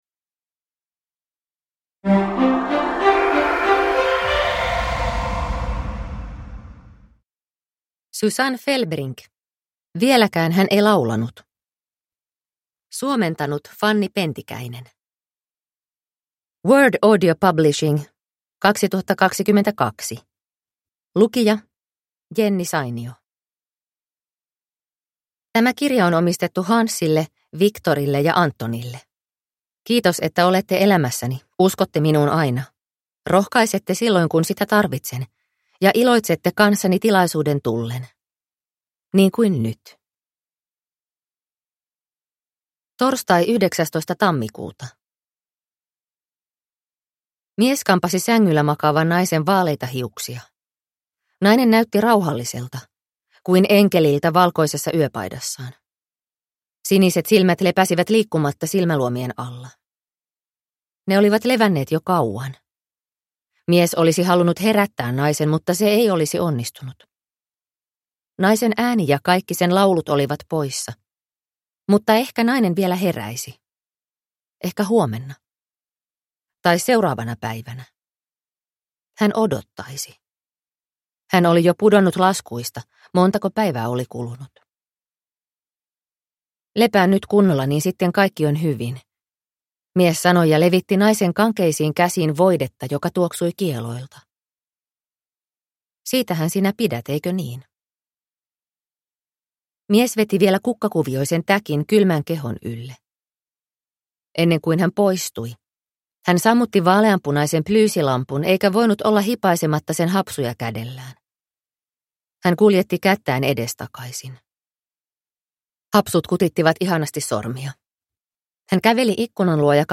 Vieläkään hän ei laulanut (ljudbok) av Susanne Fellbrink